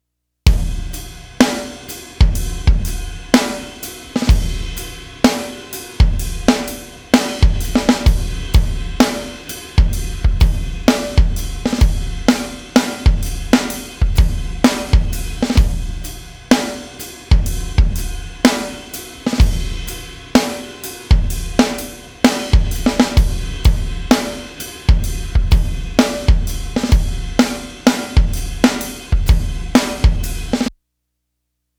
As an example I hit the REC button & tracked two loose grooves with some imperfections pretty far off the grid.
Loose Groove Two.wav
Nice drumming + great sounding kit!
Loose-Groove-Two.wav